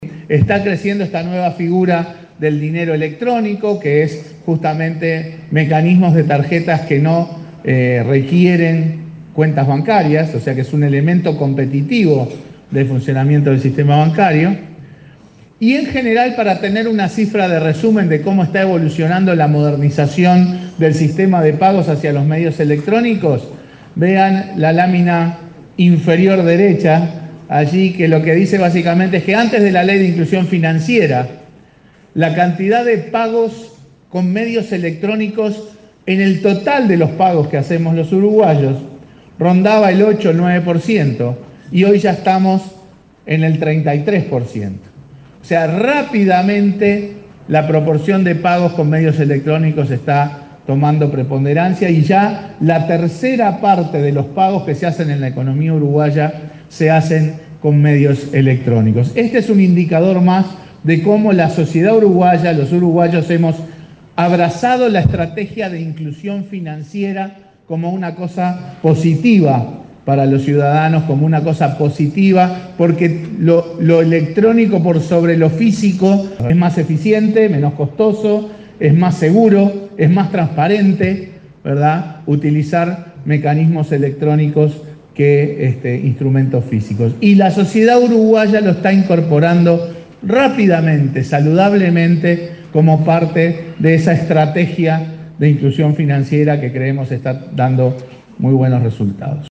Los pagos por medios electrónicos se ubican en 33 %, mientras que antes de la ley de inclusión financiera se encontraban en un 9 %, puntualizó el presidente del Banco Central, Mario Bergara, durante un foro de Asociación Cristiana de Dirigentes de Empresa.